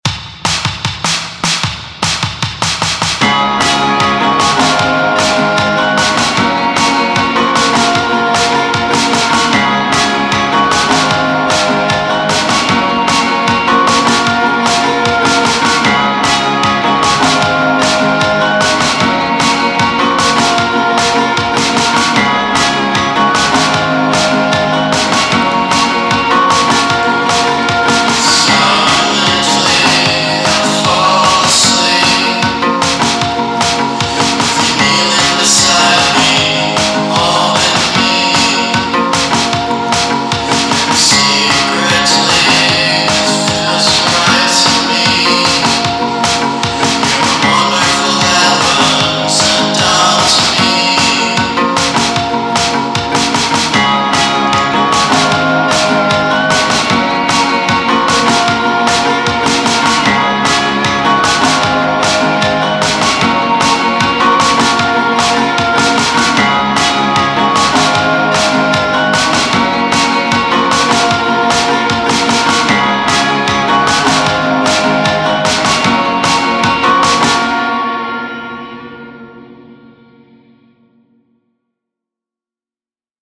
filone di bassa fedeltà